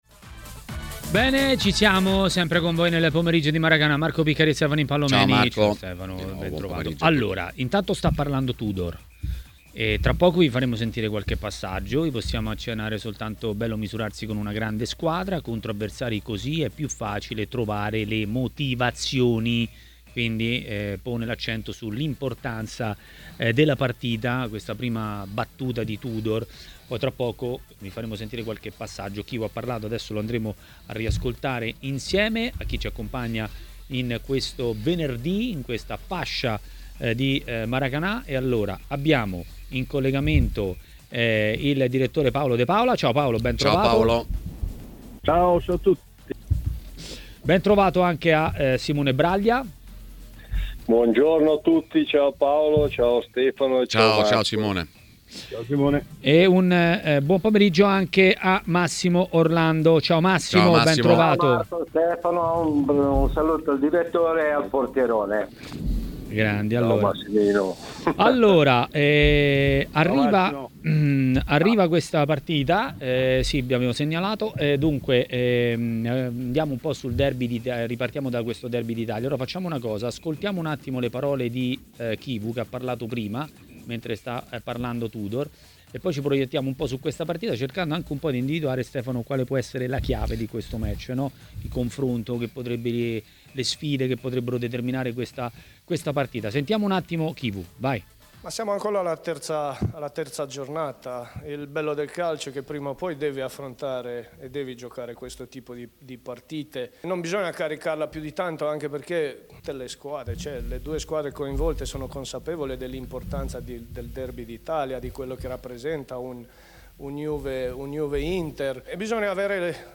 A commentare le notizie del giorno a TMW Radio, durante Maracanà, è stato l'ex calciatore Massimo Orlando.